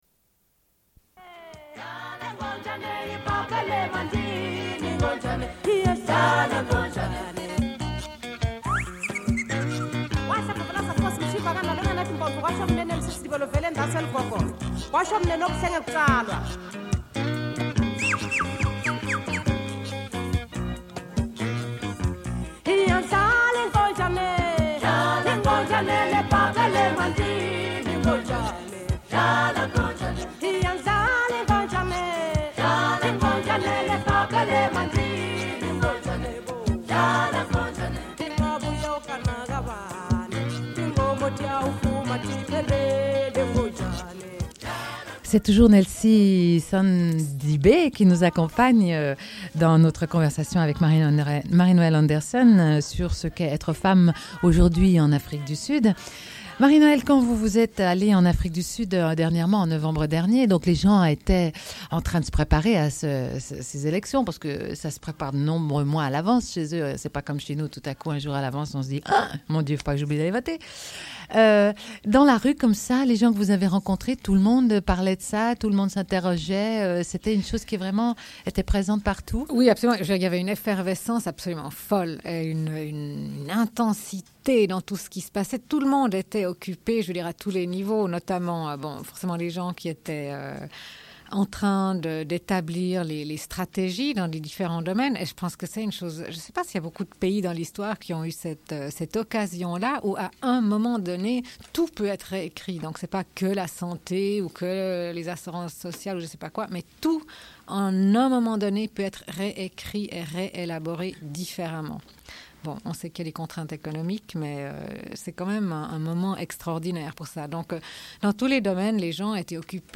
Une cassette audio, face A30:53